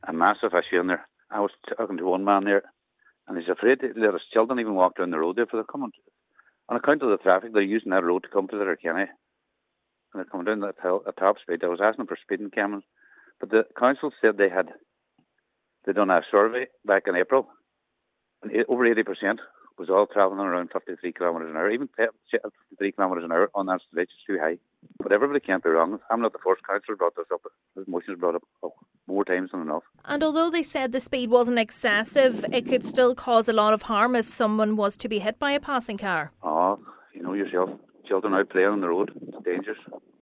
He has repeated calls for traffic calming measures to be installed: